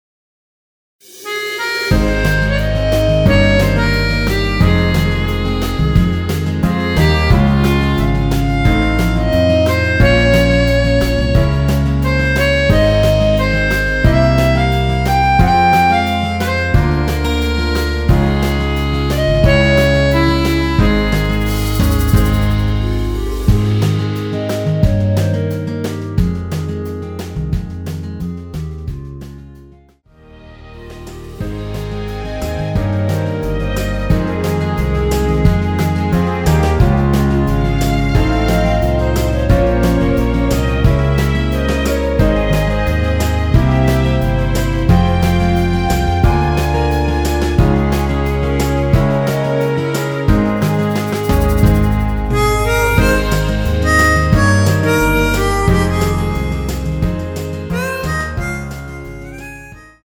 원키에서(-2)내린 멜로디 포함된 MR입니다.(미리듣기 확인)
Ab
노래방에서 노래를 부르실때 노래 부분에 가이드 멜로디가 따라 나와서
앞부분30초, 뒷부분30초씩 편집해서 올려 드리고 있습니다.
중간에 음이 끈어지고 다시 나오는 이유는